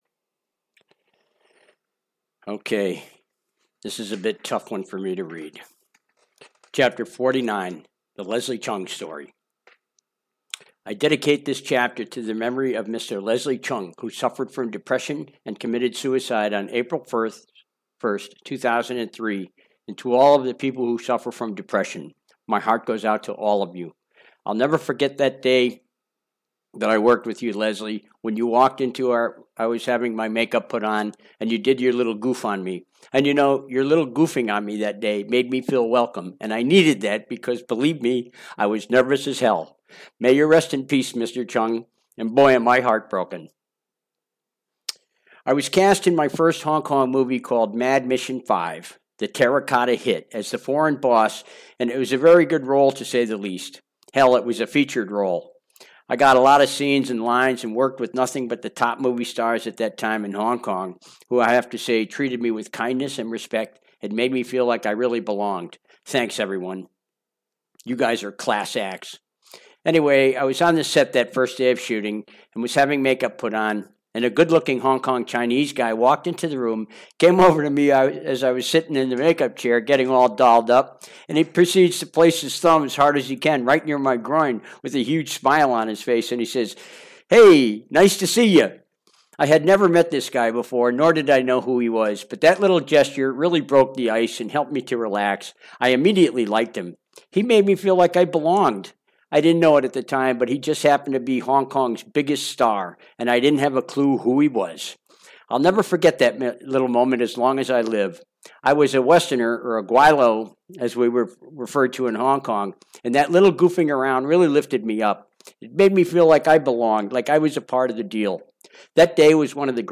Chapter 49 (Audiobook) https